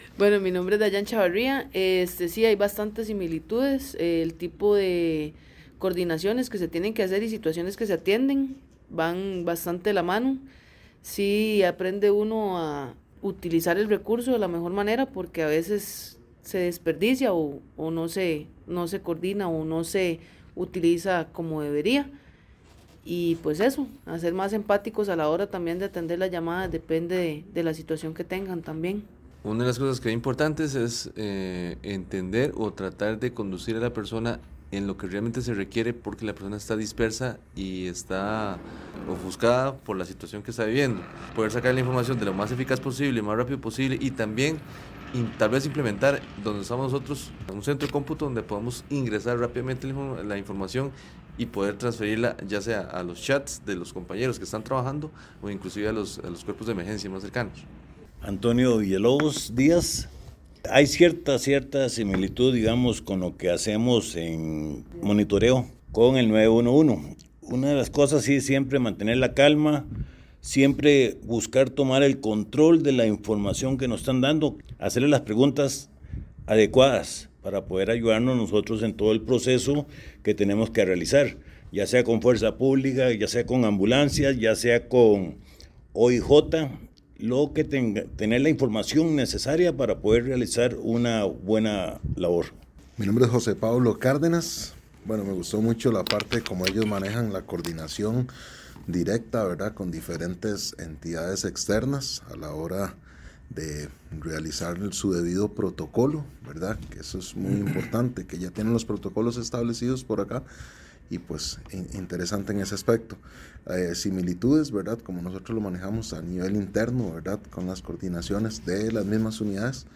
Entrevista oficiales de seguridad